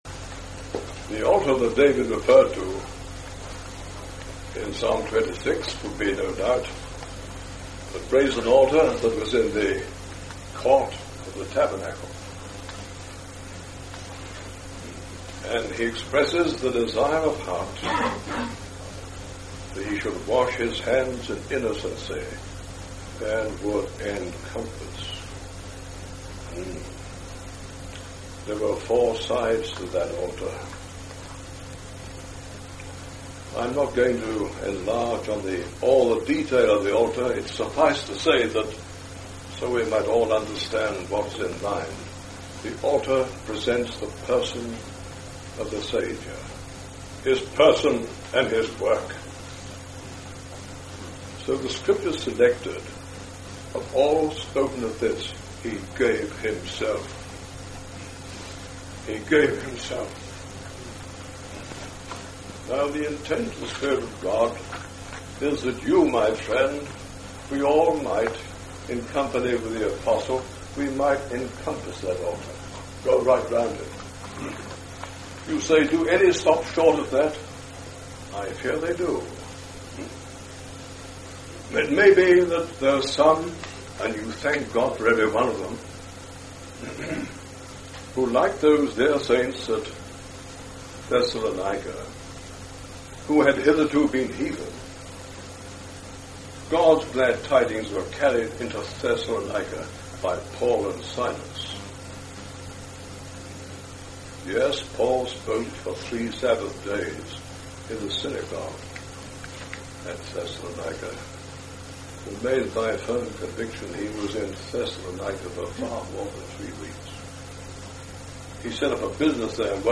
In this address, you will hear about the greatest person of all time the Lord Jesus Christ. He’s done an amazing work to bring salvation to his people.